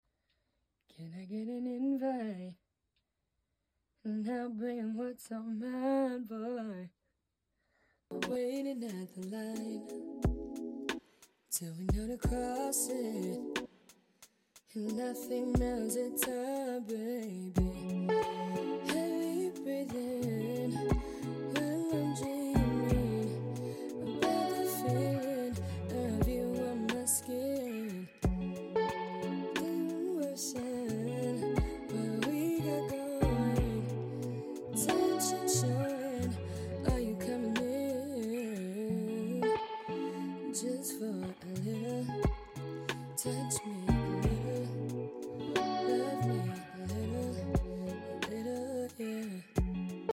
has been in the STU making her songs come to life